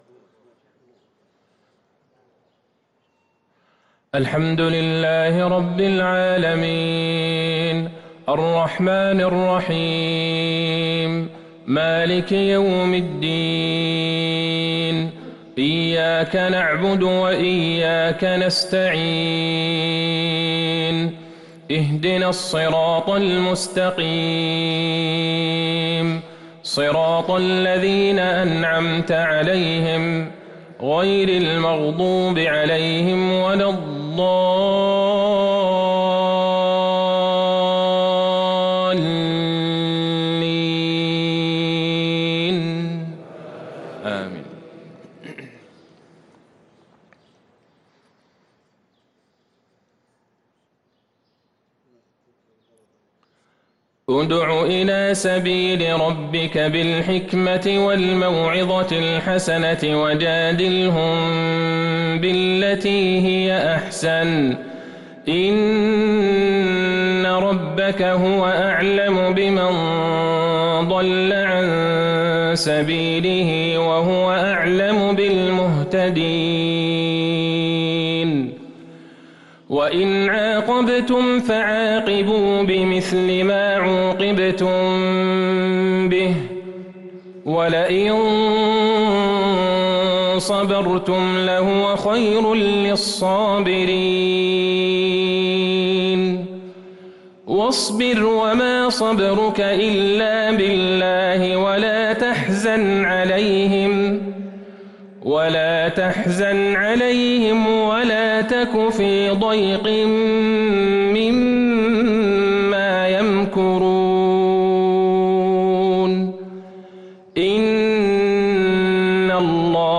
مغرب الأربعاء ٨ رجب ١٤٤٣هـ | خواتيم النحل و الكهف | Maghrib prayer from Surah An-Nahl & Al-Kahf 9-2-2022 > 1443 🕌 > الفروض - تلاوات الحرمين